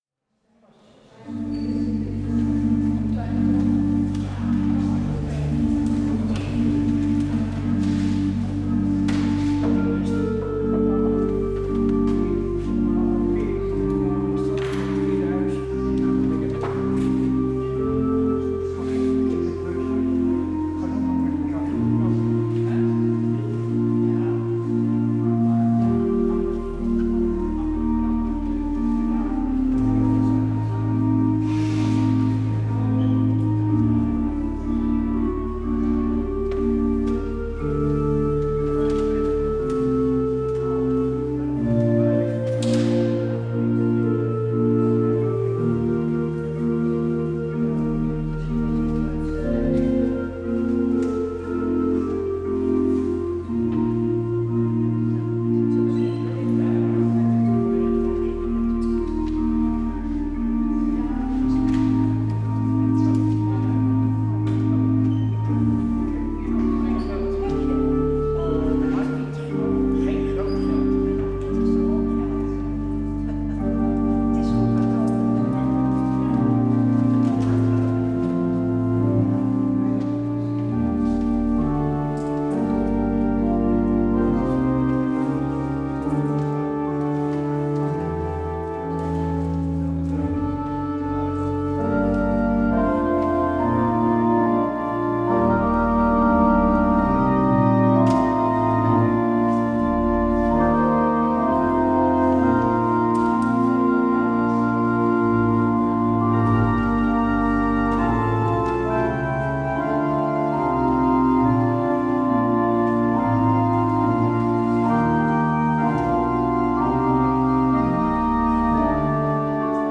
 Beluister deze kerkdienst hier: Alle-Dag-Kerk 25 maart 2026 Alle-Dag-Kerk https